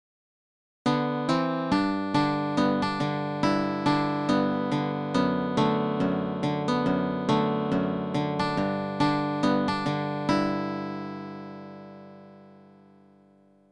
MIDI Tab Samples...
American Folk